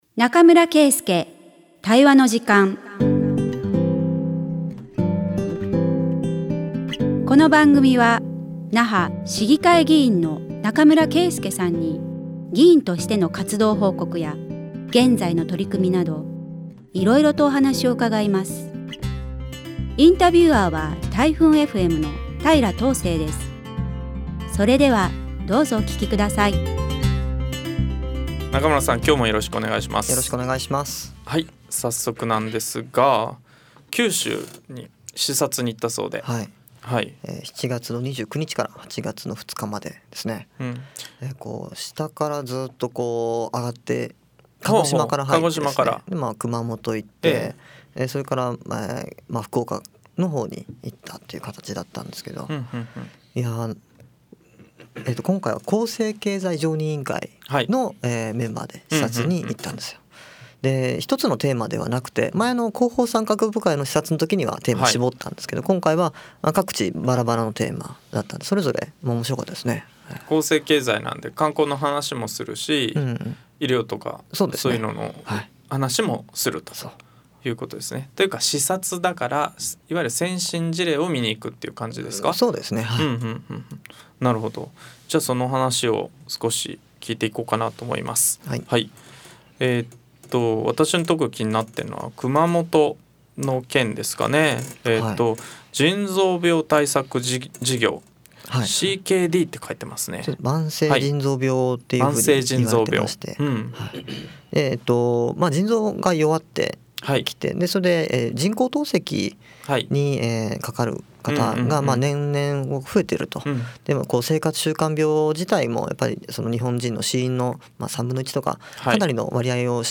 140905中村圭介対話の時間vol.14 那覇市議会議員中村圭介が議員活動や現在の取組みを語る20分